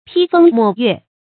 批風抹月 注音： ㄆㄧ ㄈㄥ ㄇㄛˇ ㄩㄝˋ 讀音讀法： 意思解釋： 猶言吟風弄月。